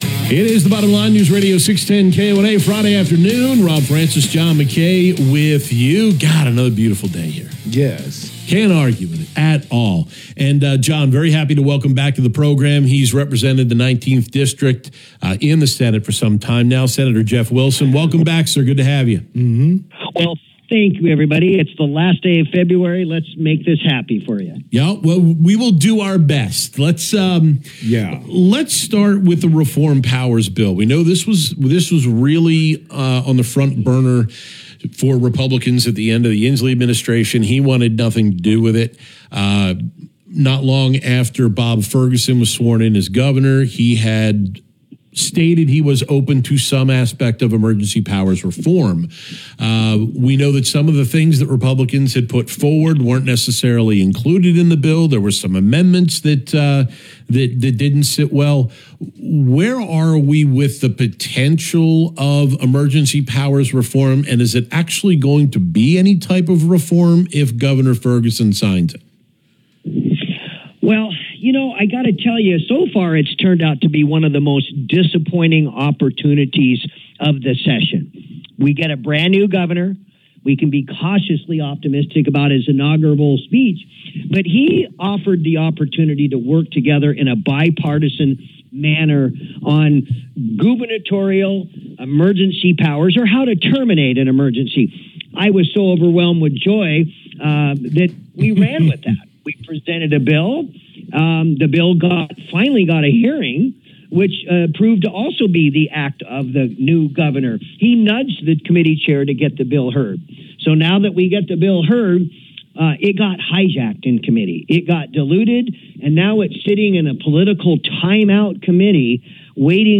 SRC On Air: Sen. Jeff Wilson on KONA Radio in the Tri Cities - Senate Republican Caucus